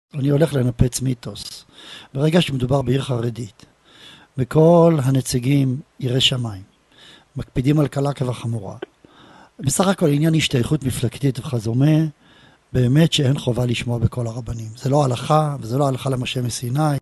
במהלך שיעורו השבועי ברדיו